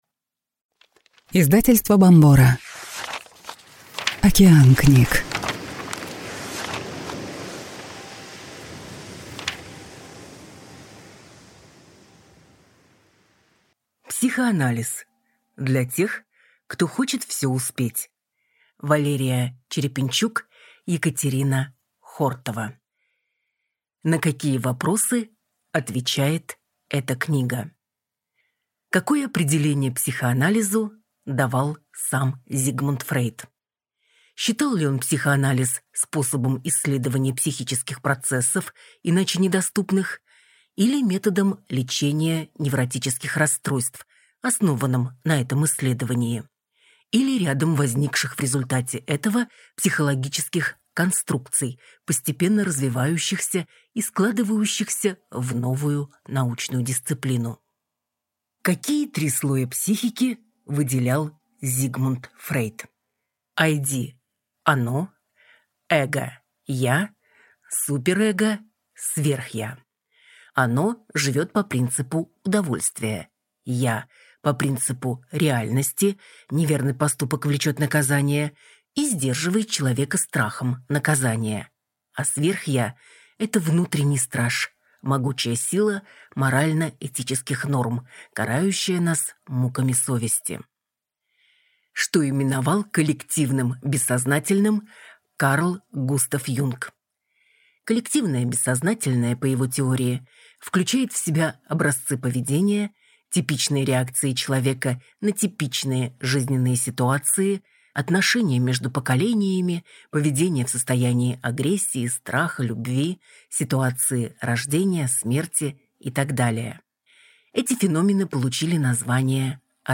Аудиокнига Психоанализ. Для тех, кто хочет все успеть | Библиотека аудиокниг